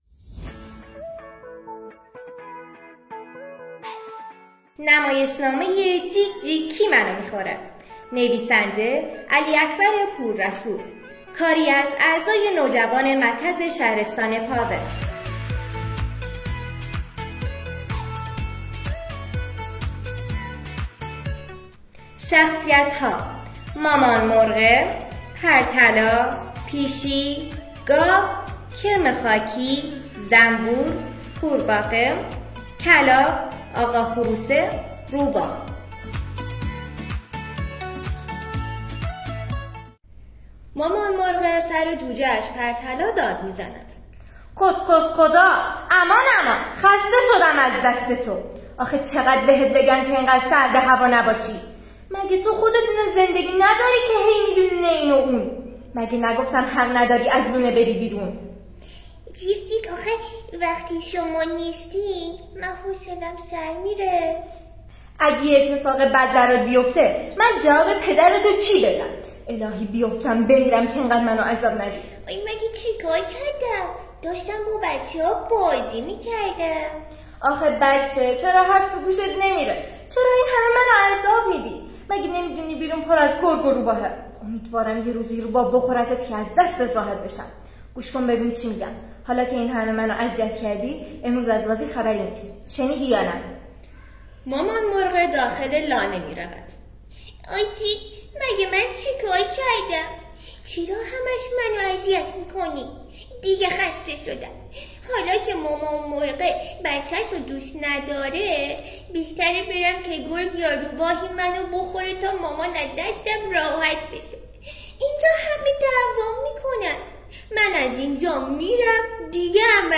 نمایشنامه‌خوانی «جیک جیک کی منو می‌خوره؟» در مرکز پاوه